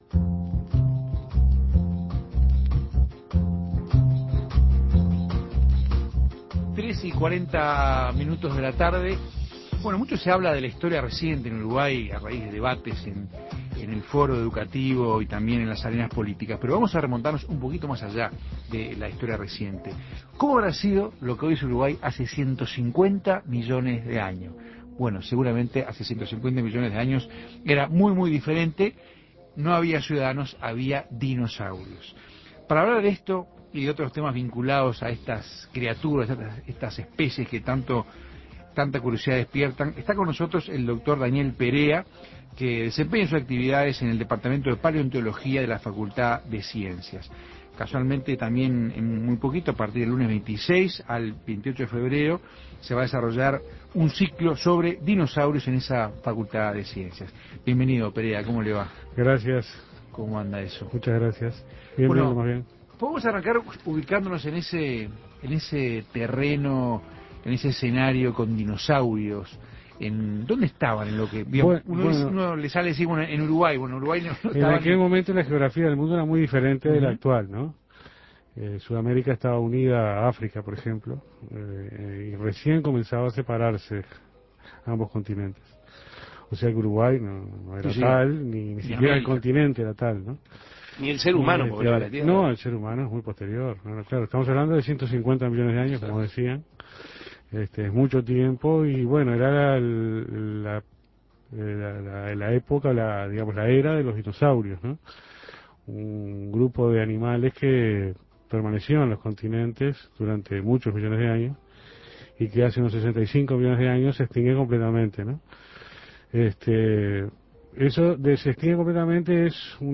se entrevistó el especialista